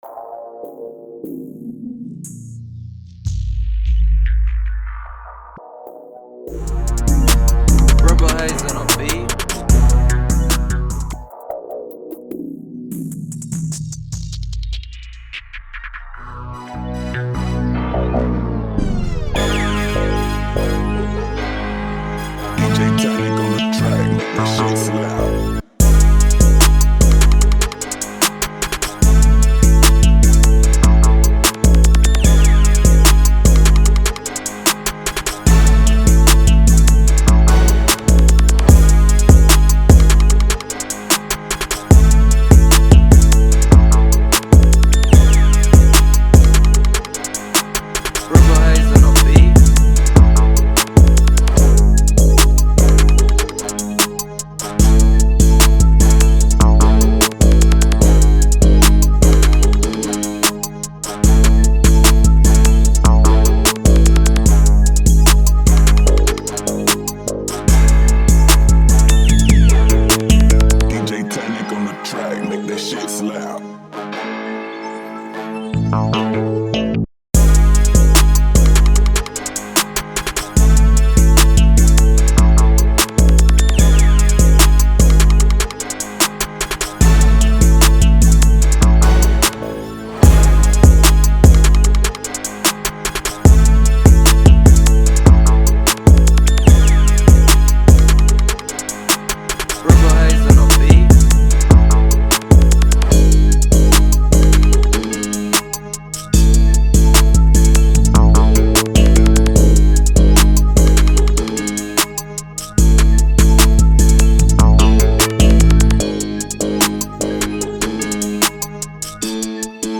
Trap Instrumentals